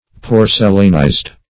porcelainized - definition of porcelainized - synonyms, pronunciation, spelling from Free Dictionary
Search Result for " porcelainized" : The Collaborative International Dictionary of English v.0.48: Porcelainized \Por"ce*lain*ized\, a. (Geol.)